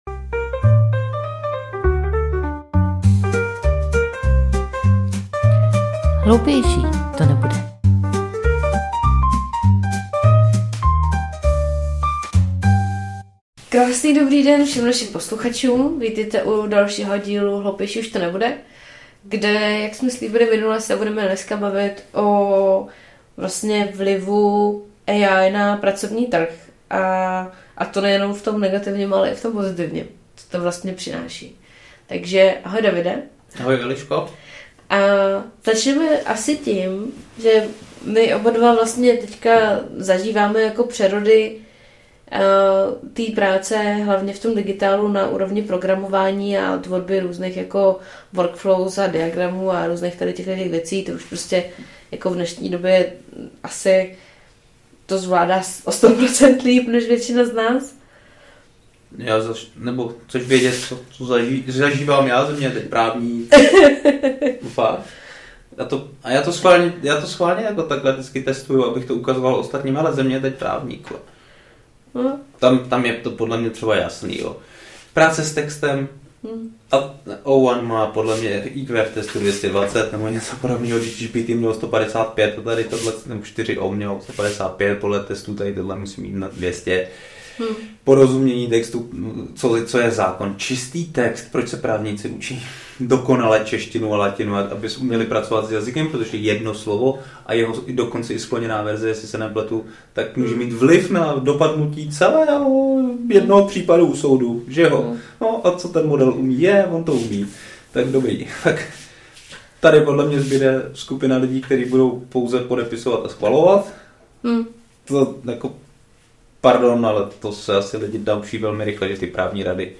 Nezávazná diskuze dvou lidí, kteří se denně pohybují ve světě technologií.